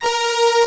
crunk efx 9.wav